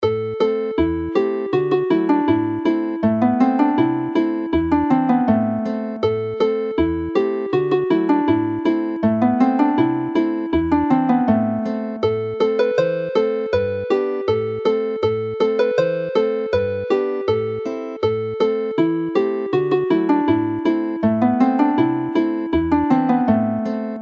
Alawon Cymreig - Set Dowlais - Welsh folk tunes to play
The song was included in Dm in its own monthly set, one of the earliest in this collection but is easier for male voices to sing in the key presented here, Am.